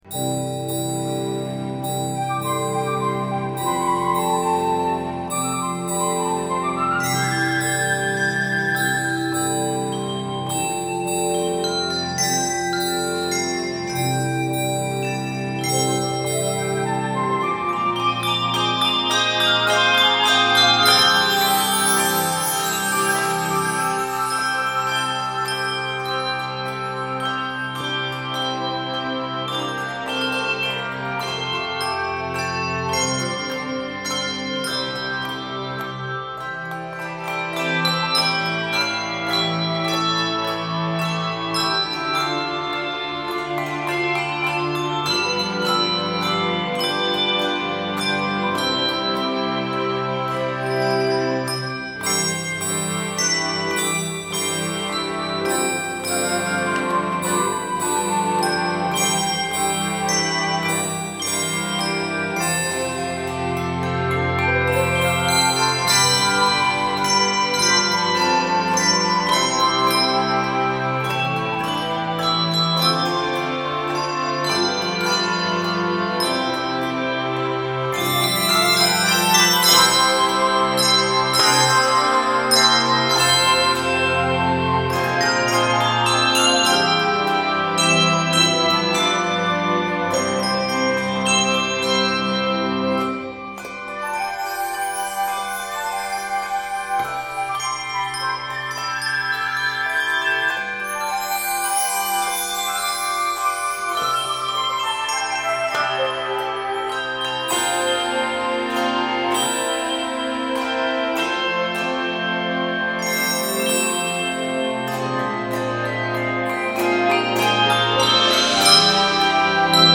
Begins in C major, modulates to F Major, then to Bb Major.